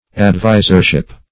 Advisership \Ad*vis"er*ship\, n. The office of an adviser.